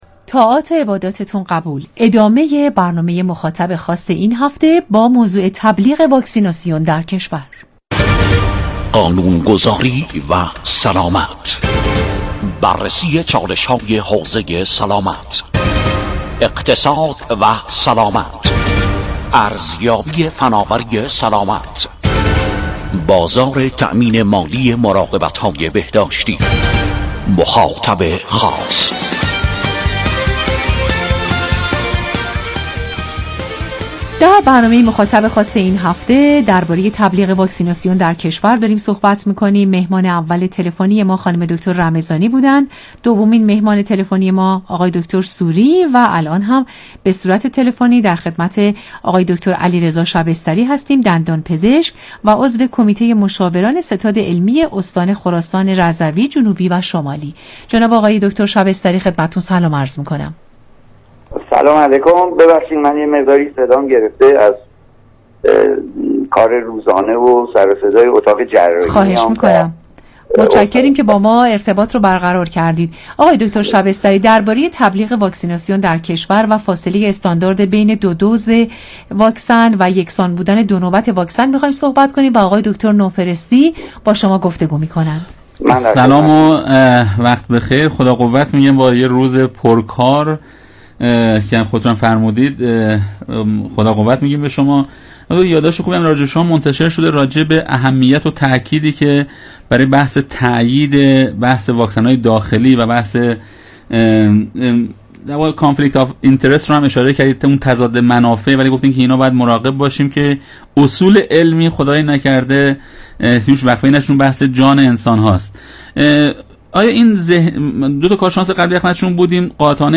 شرکت در گفتگوی خبری رادیو سلامت
دانلود فایل صوتی مصاحبه